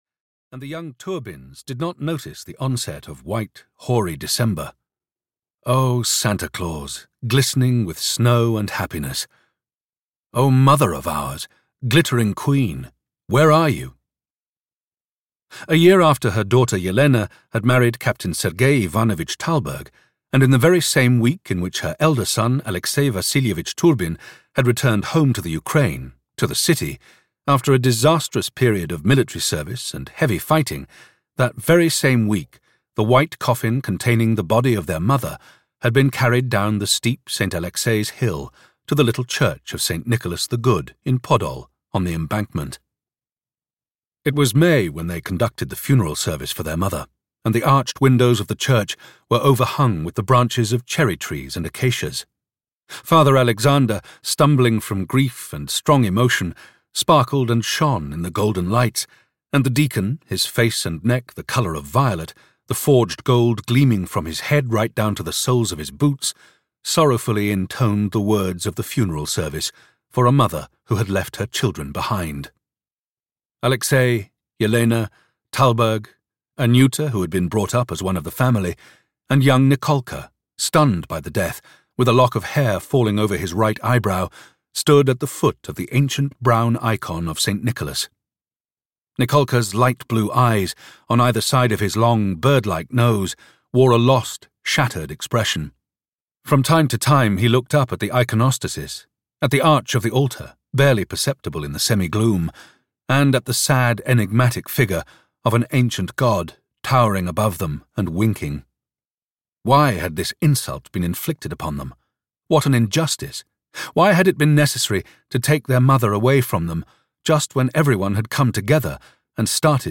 The White Guard (EN) audiokniha
Ukázka z knihy